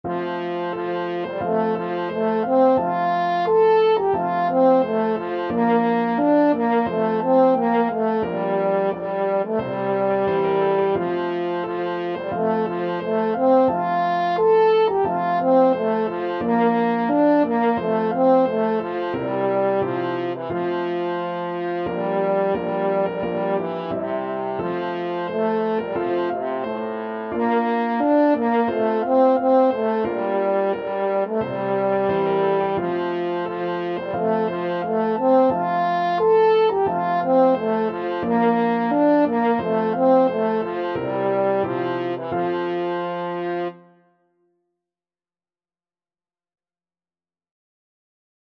4/4 (View more 4/4 Music)
F major (Sounding Pitch) C major (French Horn in F) (View more F major Music for French Horn )
French Horn  (View more Easy French Horn Music)
Traditional (View more Traditional French Horn Music)
Scottish